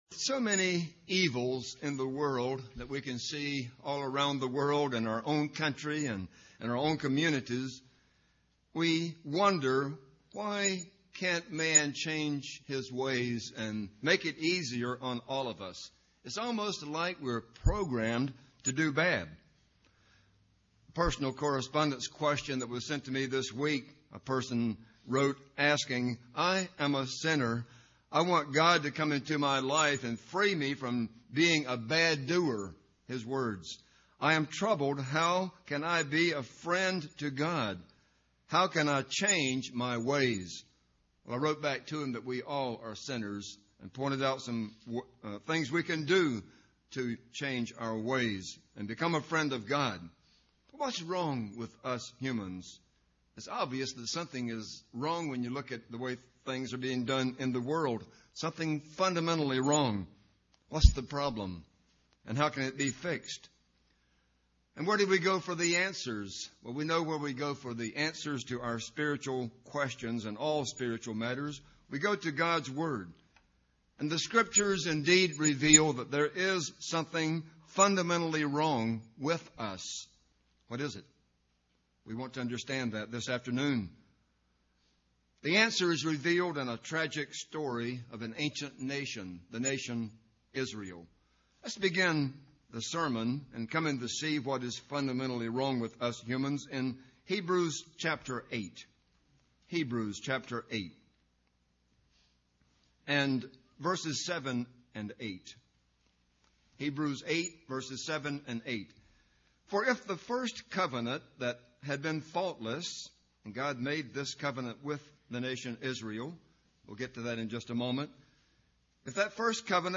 This sermon was given at the Jekyll Island, Georgia 2007 Feast site.